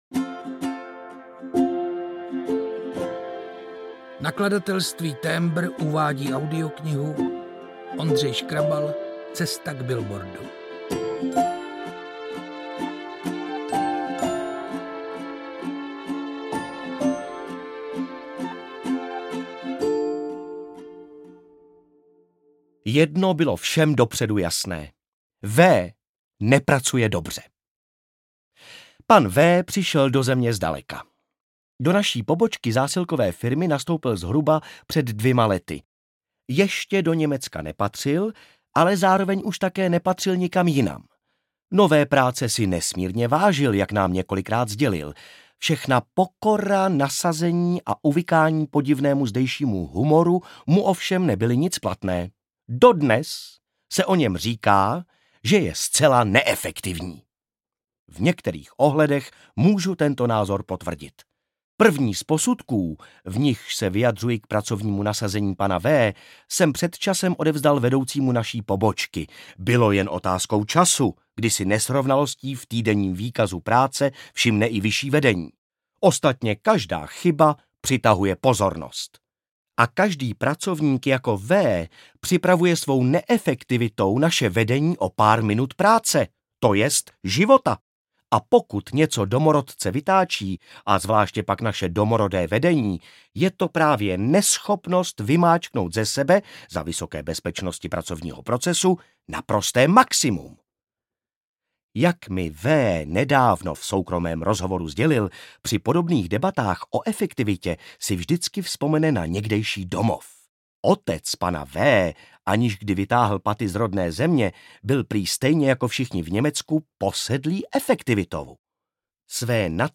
Cesta k billboardu audiokniha
Ukázka z knihy